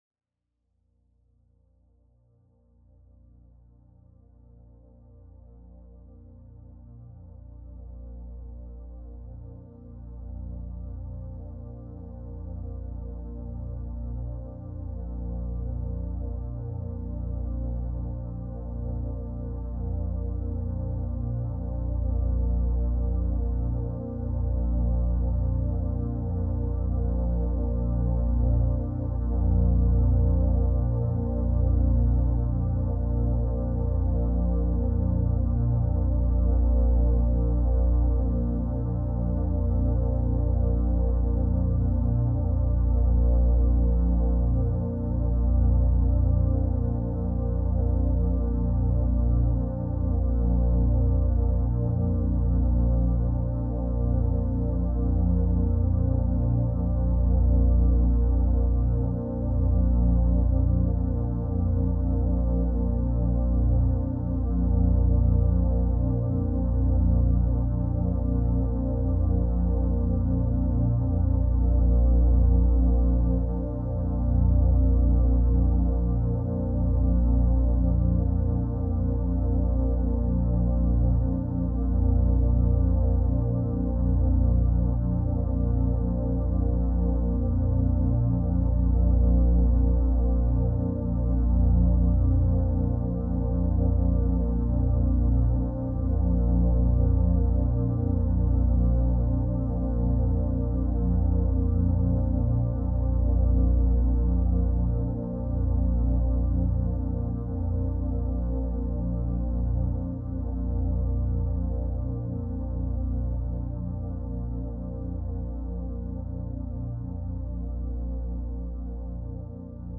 powerful vibrational harmonics
Non-verbal.